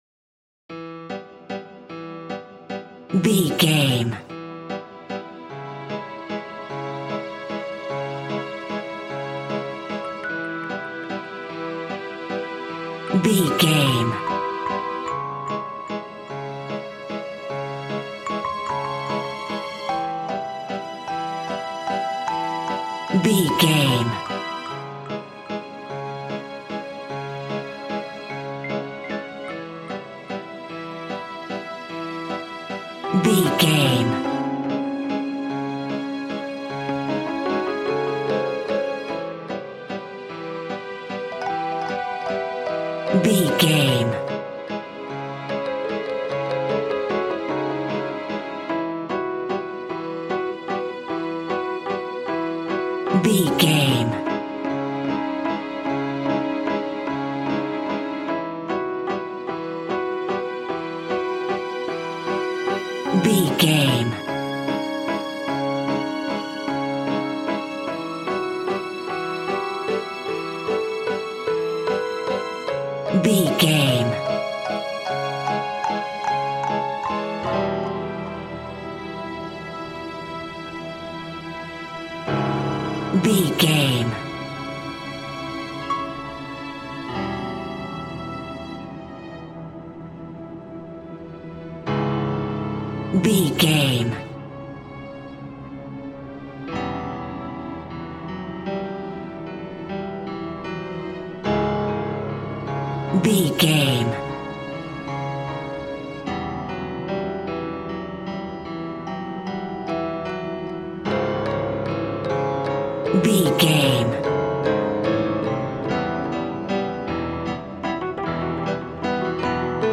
Clown Horror Music Cue.
Aeolian/Minor
ominous
dark
eerie
piano
strings
synth
pads